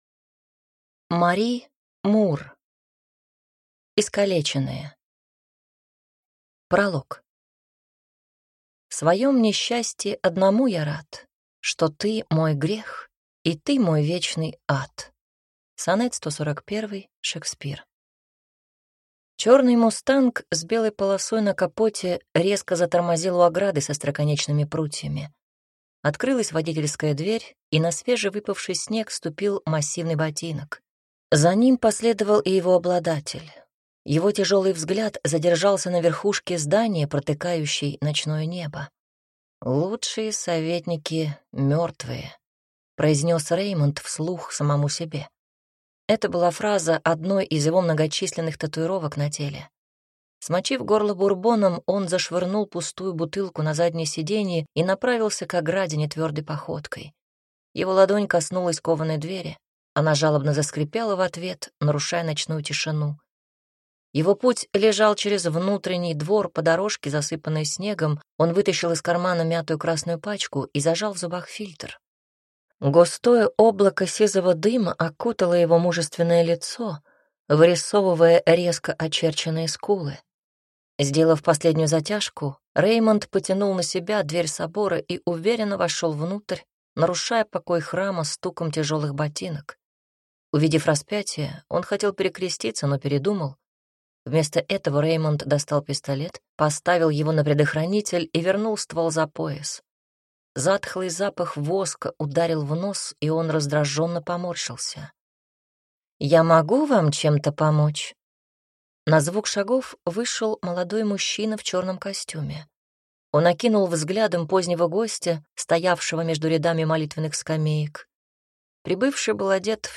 Аудиокнига Искалеченные | Библиотека аудиокниг
Прослушать и бесплатно скачать фрагмент аудиокниги